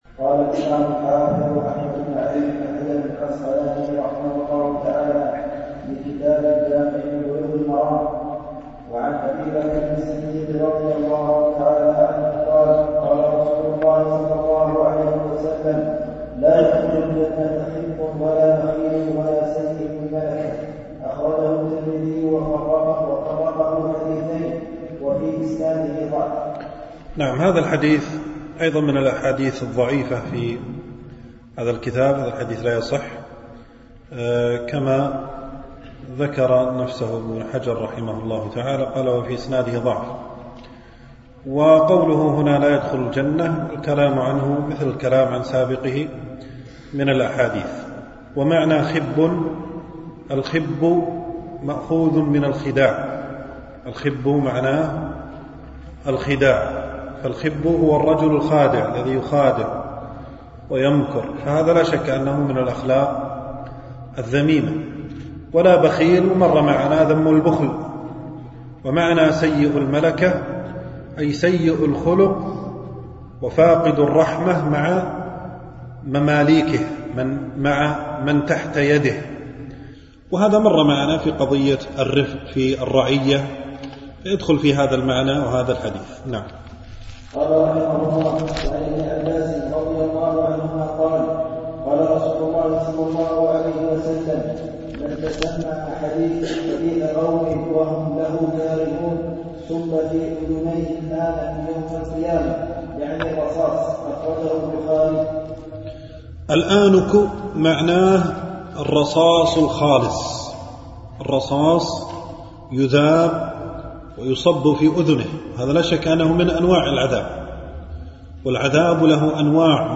شرح كتاب الجامع من بلوغ المرام ـ الدرس الخامس
دروس مسجد عائشة (برعاية مركز رياض الصالحين ـ بدبي)